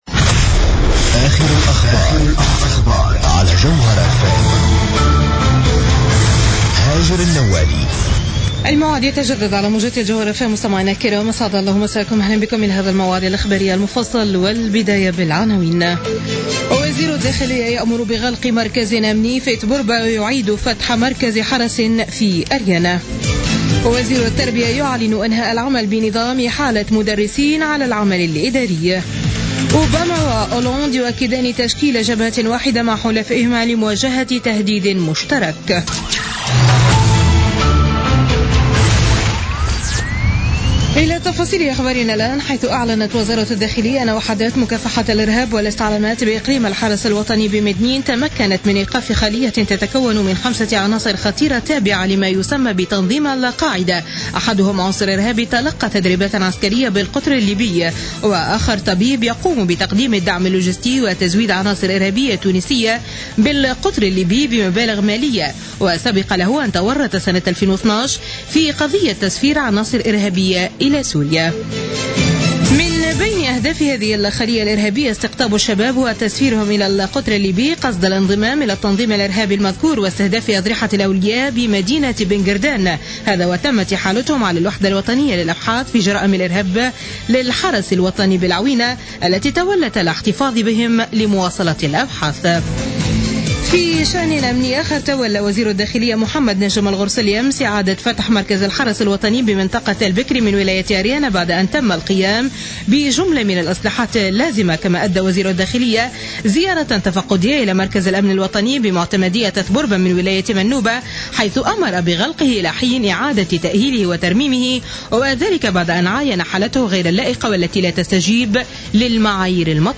نشرة أخبار منتصف الليل ليوم الأحد 6 ديسمبر 2015